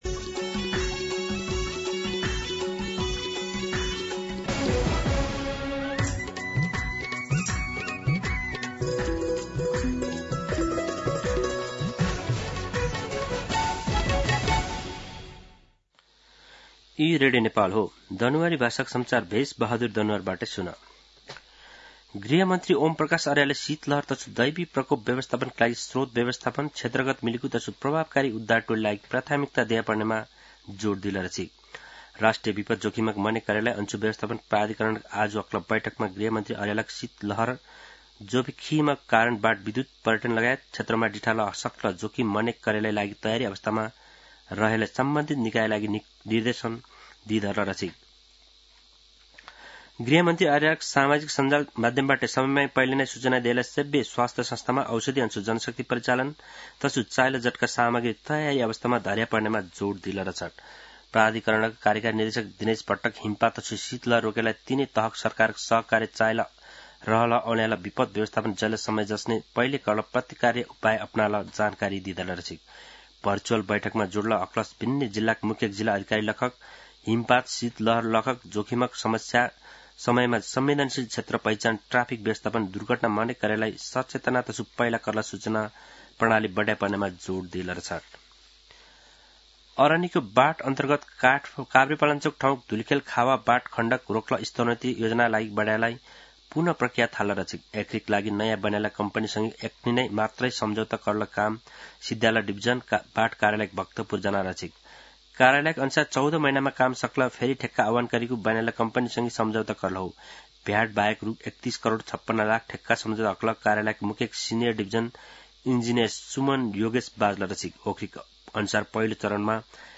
दनुवार भाषामा समाचार : ३ मंसिर , २०८२
Danuwar-News-2.mp3